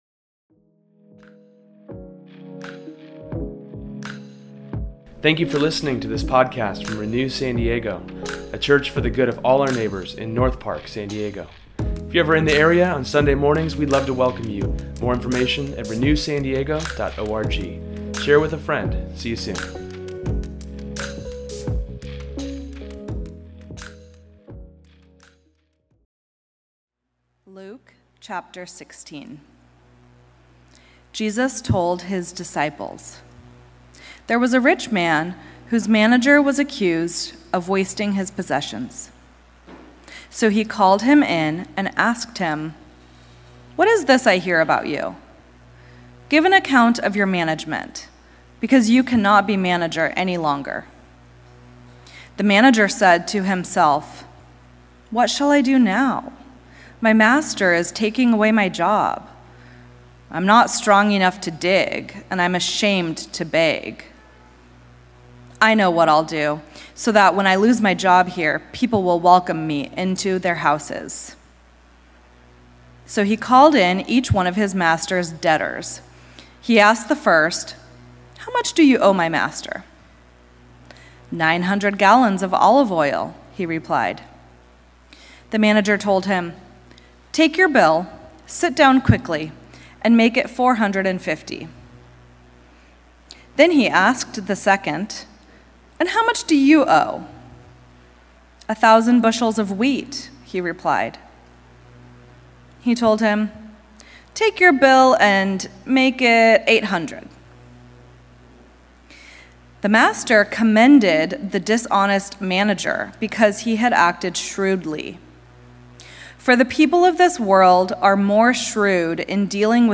Today’s sermon touches on how money is a powerful factor in our life, but we should look to God for help with dealing with it and other things.